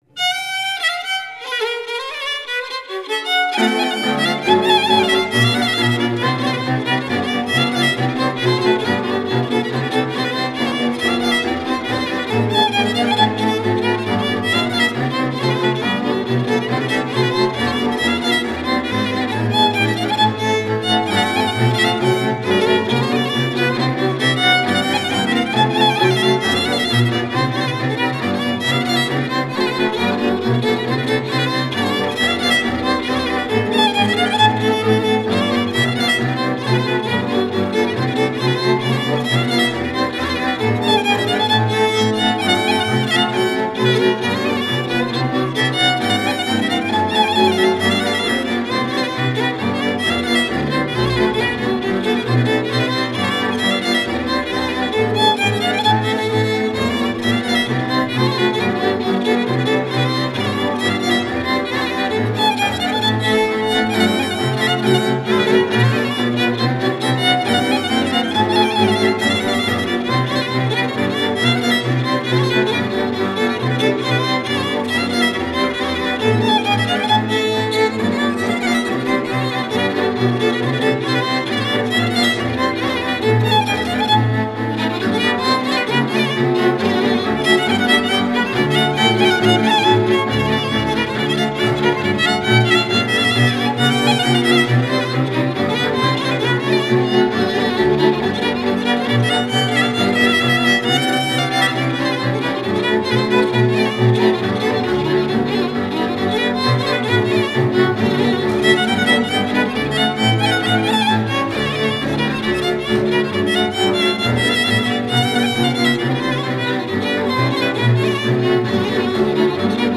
Grupul instrumental din Viișoara